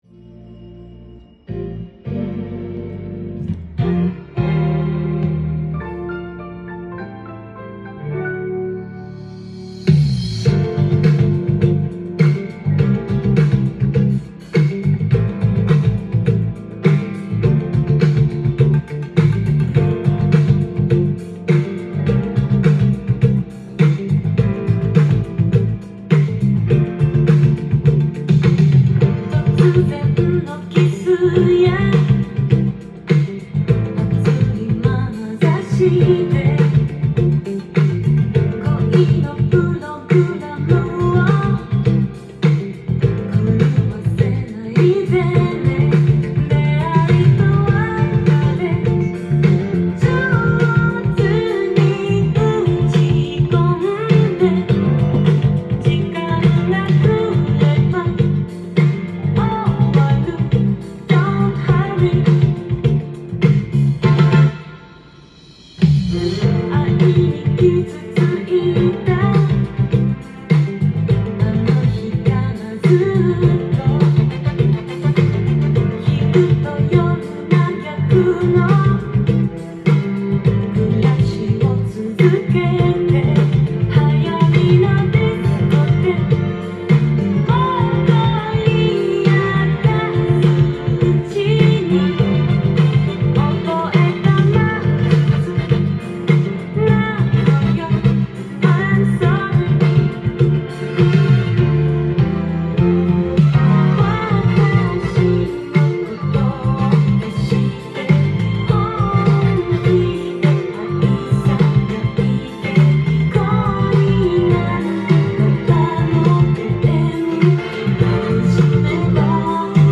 ジャンル：CITYPOP / POP
店頭で録音した音源の為、多少の外部音や音質の悪さはございますが、サンプルとしてご視聴ください。